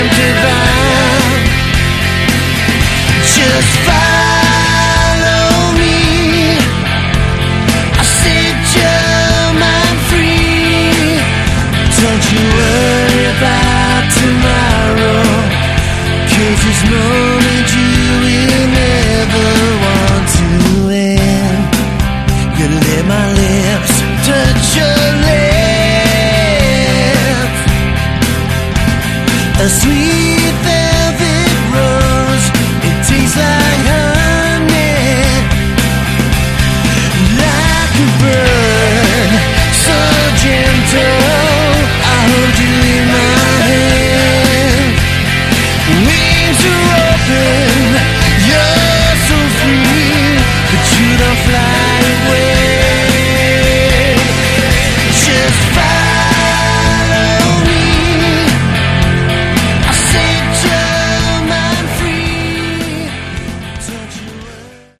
Category: Hard Rock
vocals
guitars, backing vocals
drums